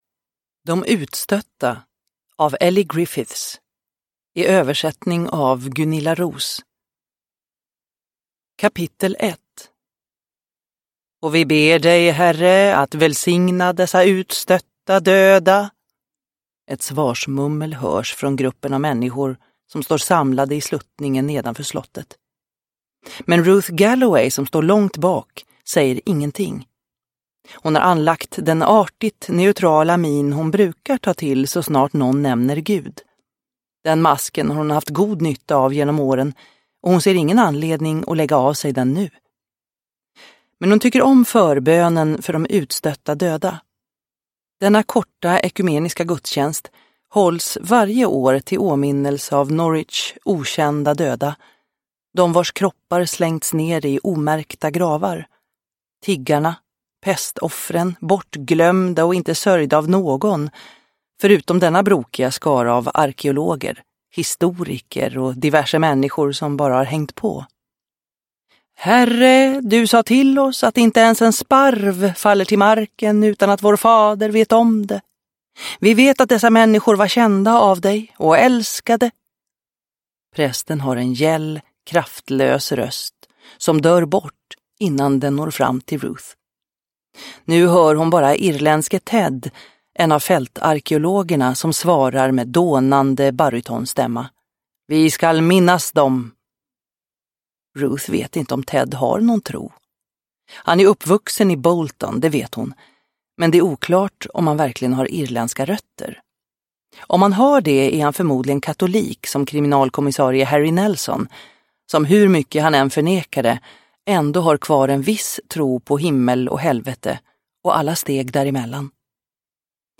De utstötta – Ljudbok – Laddas ner